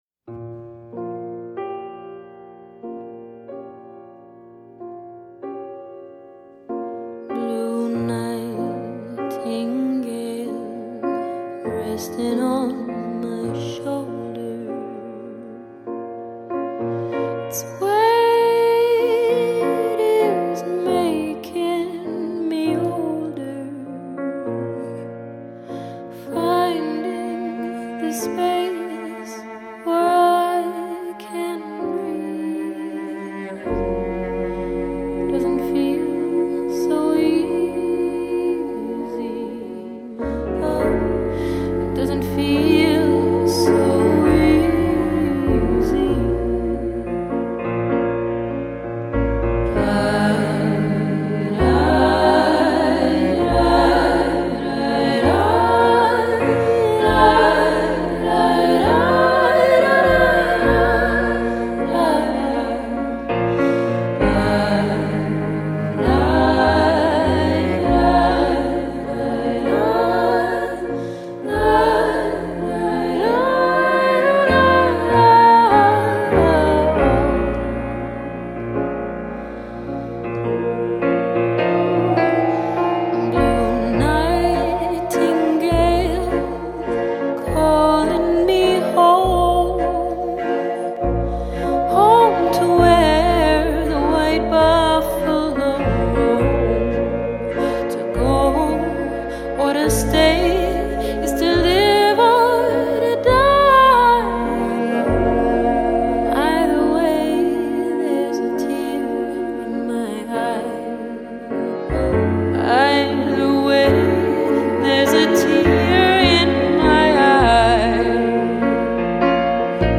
fairy tale pop
Tags2010s 2012 Canada pop